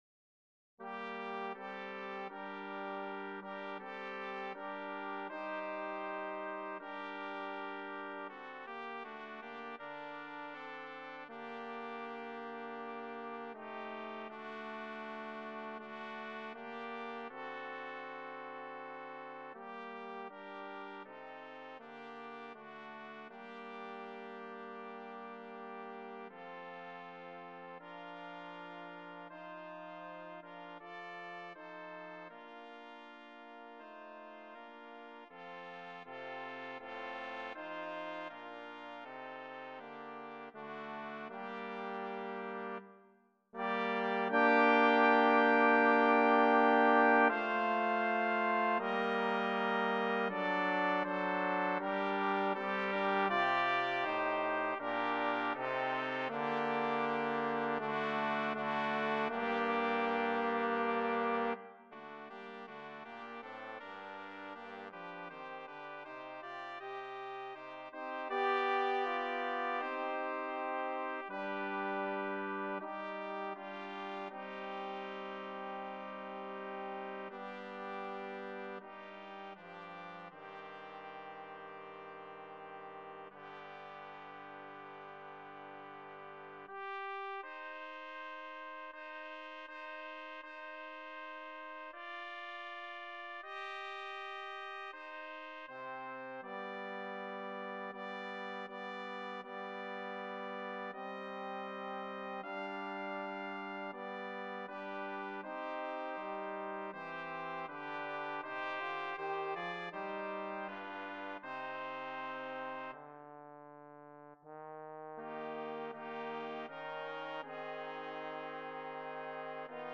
BRASS QUARTET
2 TRUMPETS, 2 TROMBONES